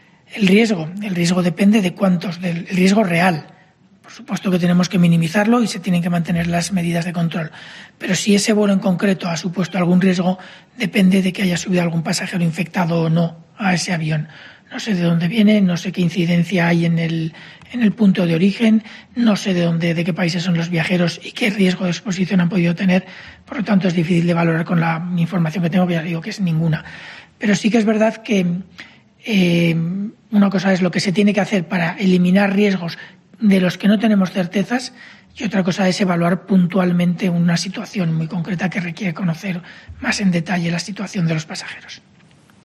Durante la rueda de prensa diaria que ofrece en Moncloa para informar del desarrollo de la pandemia, ha reconocido que no tiene información de lo ocurrido en este vuelo en concreto aunque ha insistido en que las medidas de seguridad se deben mantener "en todas partes", recordando que existen instrucciones sobre cómo se tiene que hacer en los aviones.